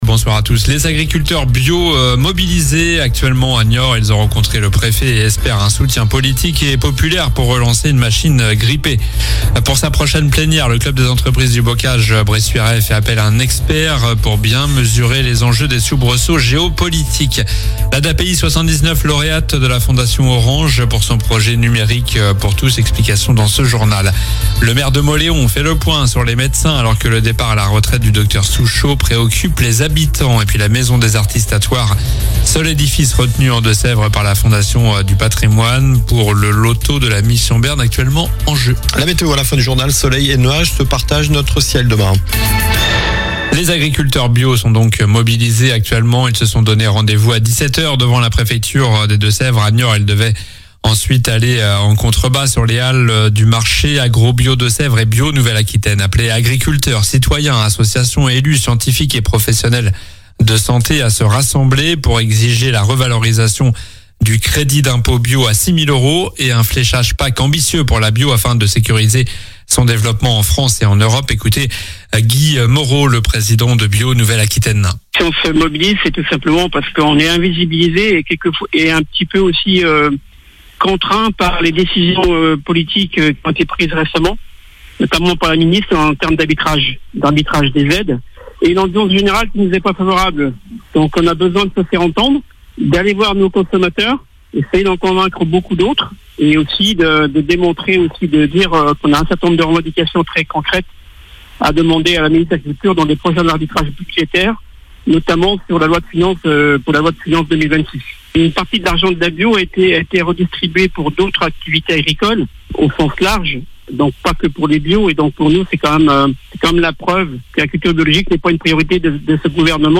Journal du mardi 23 septembre (soir)